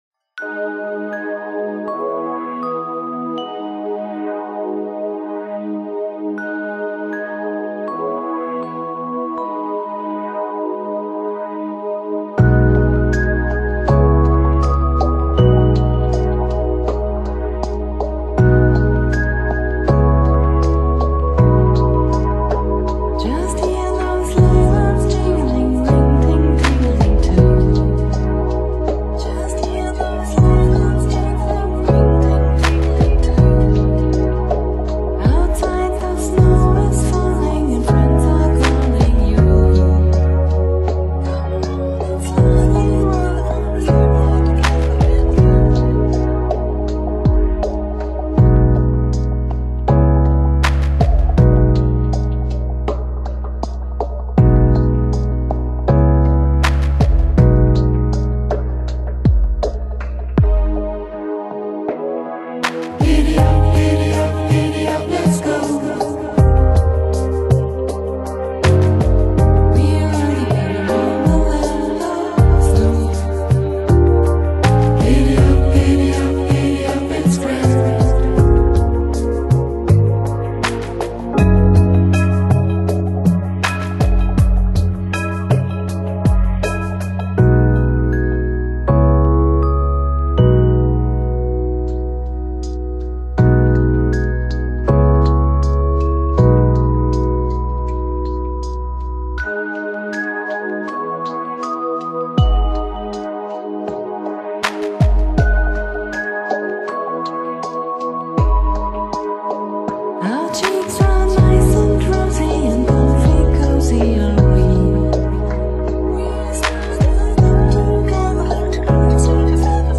Genre: Lounge / Chill Out / Downtempo